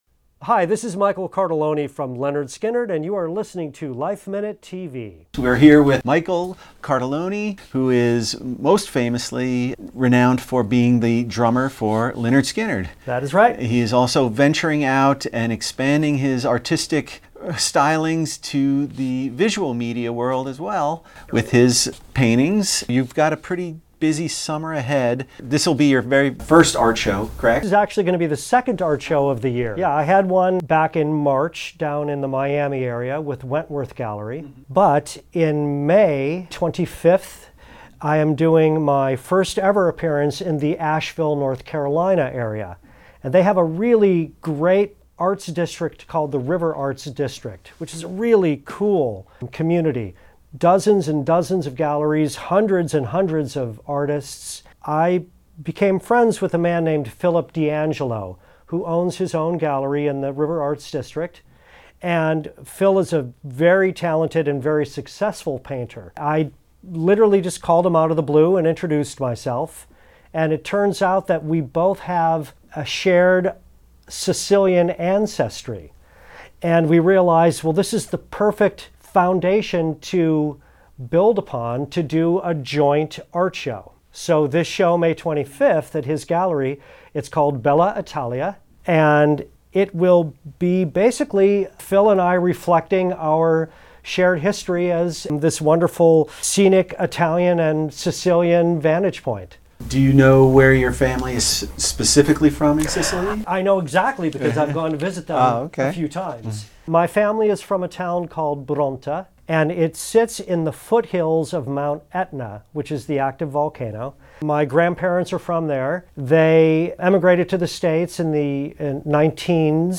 We got a taste of his beautiful work when he recently stopped by the LifeMinute Studios. His latest creations are inspired by his Sicilian heritage and a recent visit to Italy.